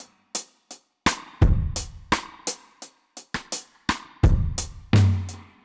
Index of /musicradar/dub-drums-samples/85bpm
Db_DrumsB_Wet_85-02.wav